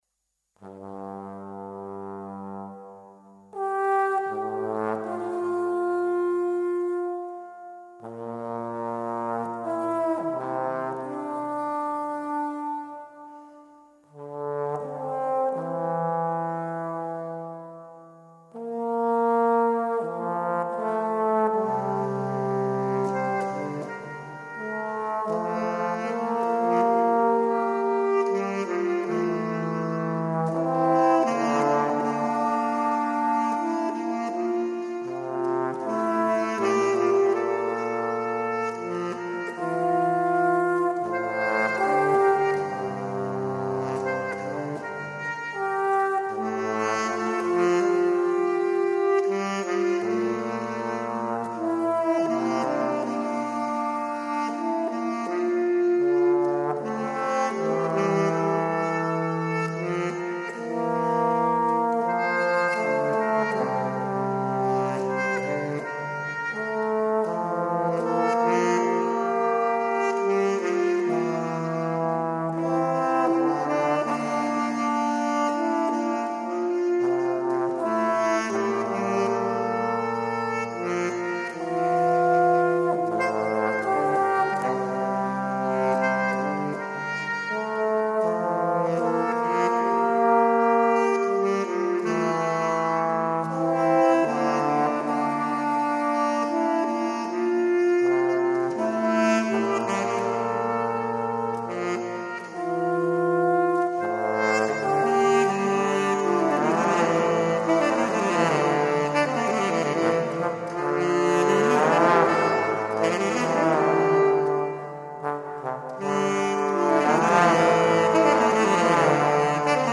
Scored for Tenor Saxophone and Trombone